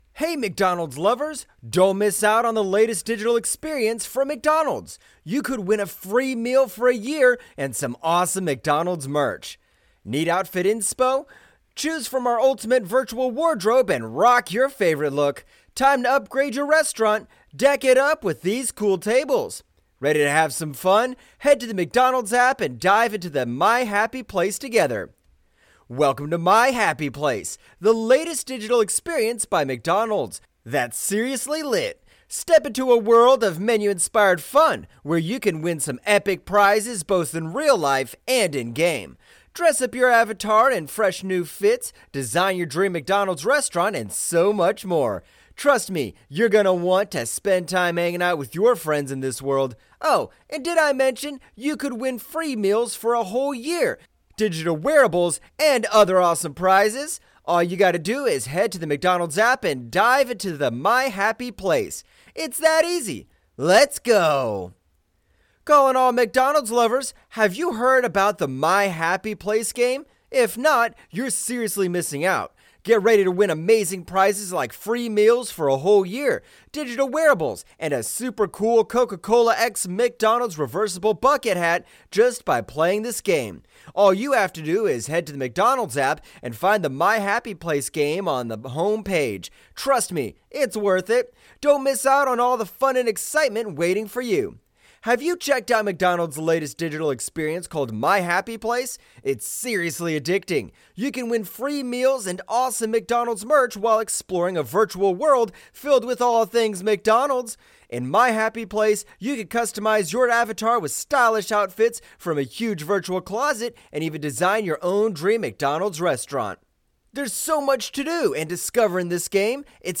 Заказать озвучку мужским голосом: дикторы мужчины онлайн | Overvoice